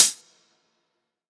ClosedHH MadFlavor 2.wav